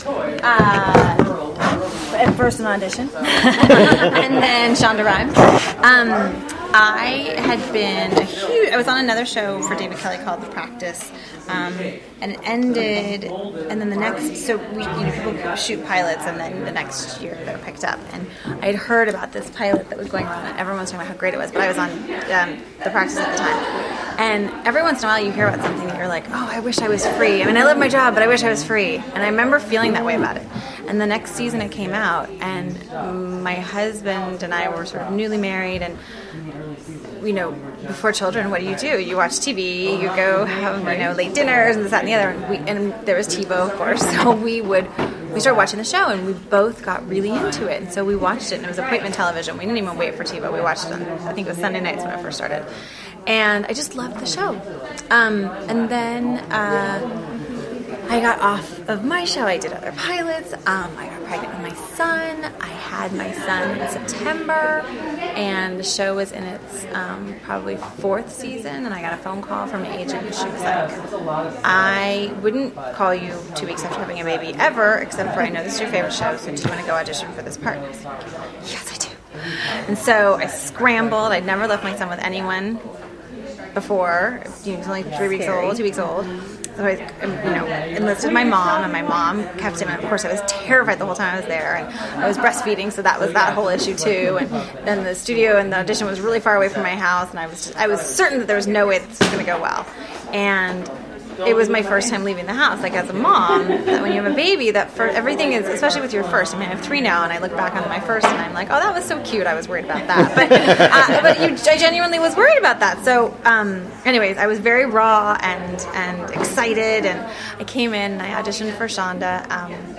We first asked her to tell us the story of getting the part of Dr. Arizona Robbins. Because she is such a cute girl and told such a great story, I have included the audio so you can listen to her tell the story.
Thank you so much for the post, especially the audio of Jessica talking about getting her part.
dr-arizona-interview.mp3